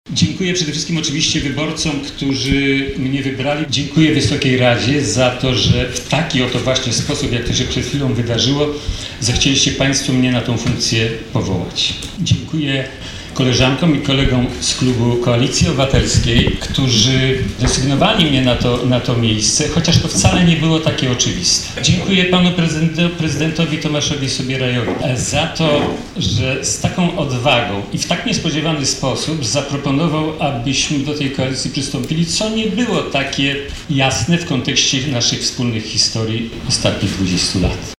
W Koszalinie o 13 rozpoczęła się uroczysta, inauguracyjna sesja Rady Miasta.
Tak Artur Wezgraj komentował swój wybór.